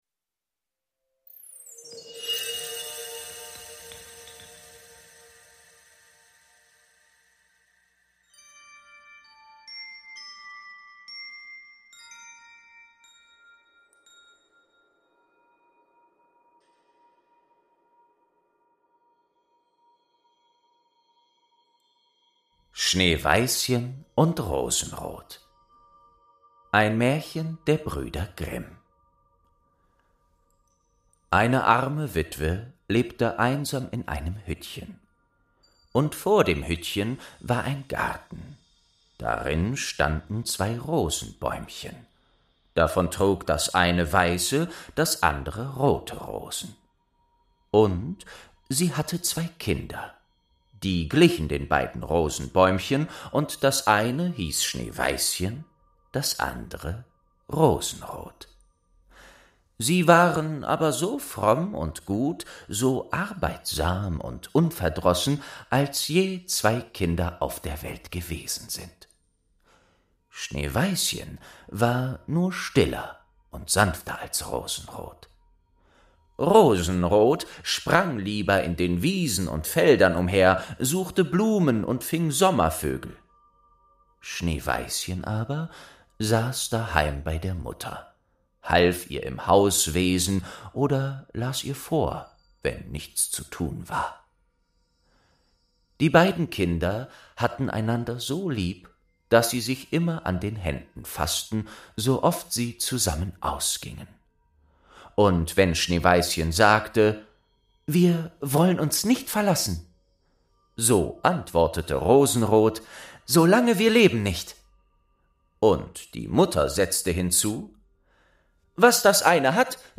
3. Schneeweißchen & Rosenrot | Staffel 2 ~ Märchen aus der verschollenen Bibliothek - Ein Hörspiel Podcast